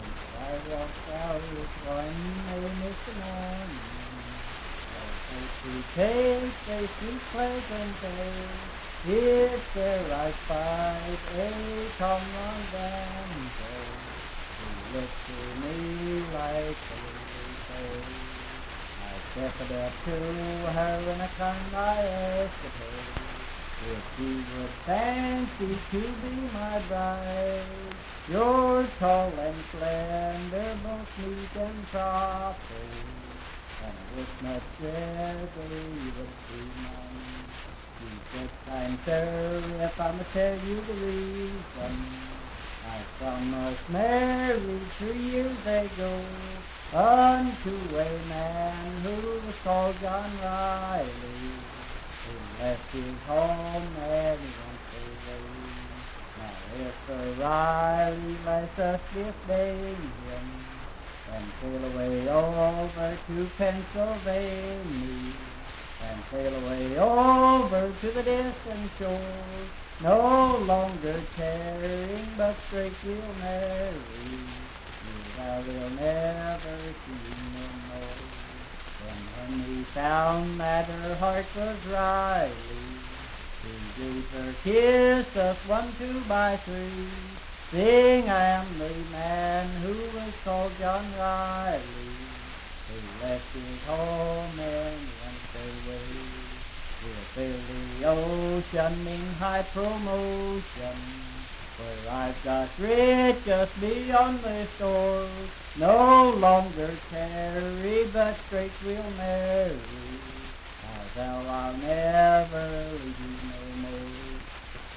Unaccompanied vocal music
Verse-refrain 6(4).
Voice (sung)